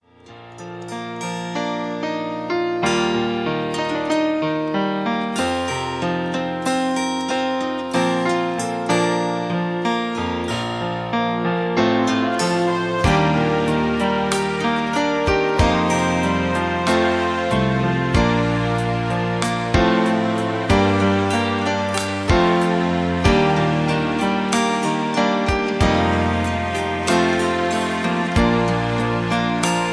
Key-Bb) Karaoke MP3 Backing Tracks
Just Plain & Simply "GREAT MUSIC" (No Lyrics).